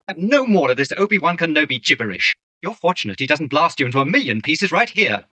c3po_noisy.wav